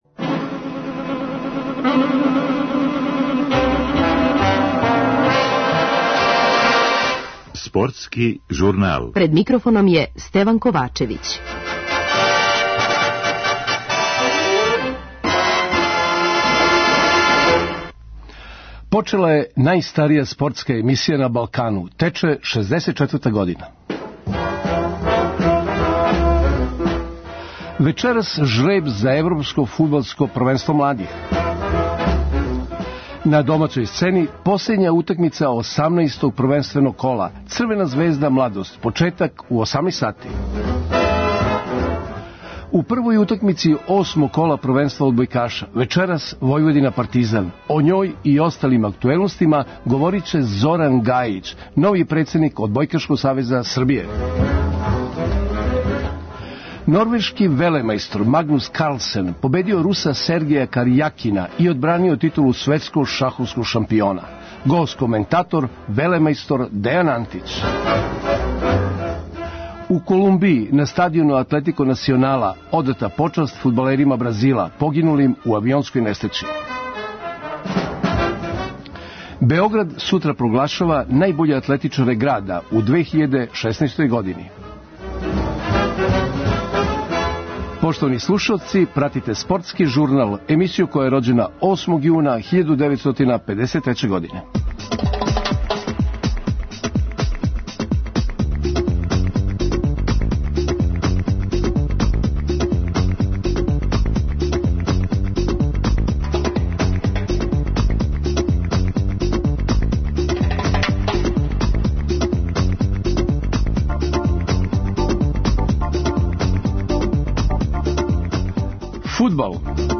Нoрвeжaнин Maгнус Кaрлсeн пoбeдиo Русa Сeргeja Кaрjaкинa и oдбрaниo титулу свeтскoг шaхoвскoг шaмпиoнa. Гoст кoмeнтaтoр вeлeмajстoр